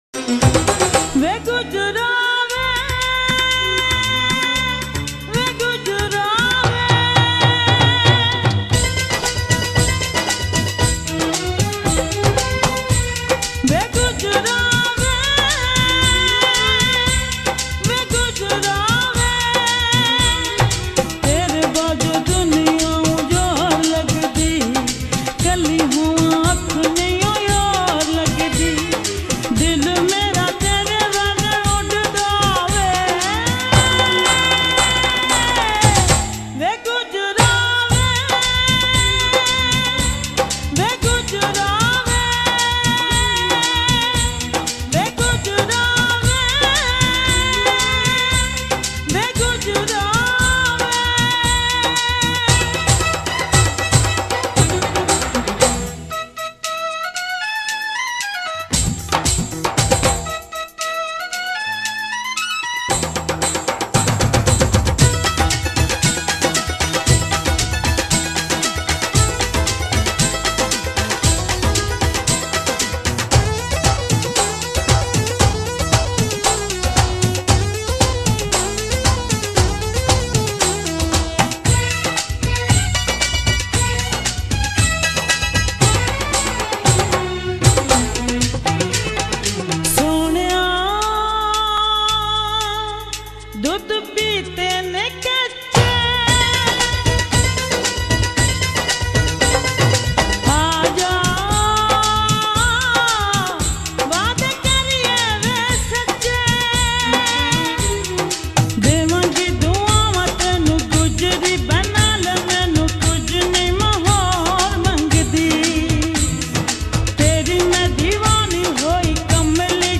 full mujra song